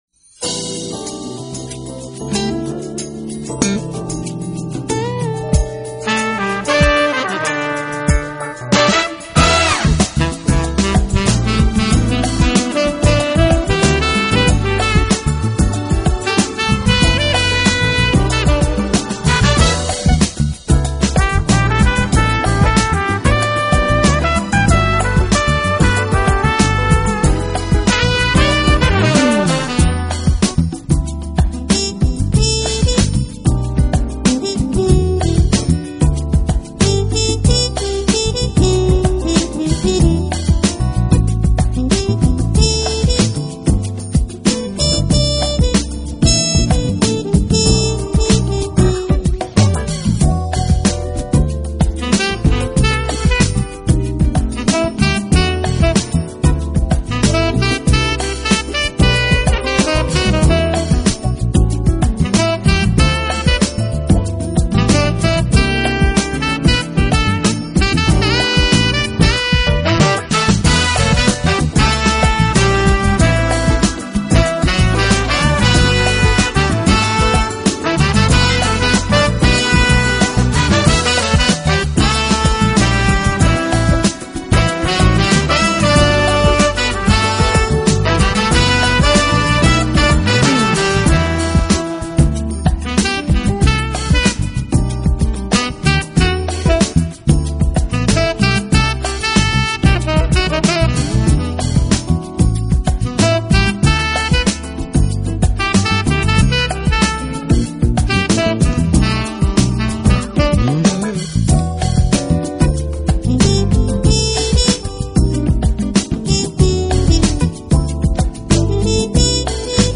Smooth, Jazz